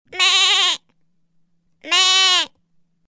Звуки козла
Звук мычания козленка бе-е-е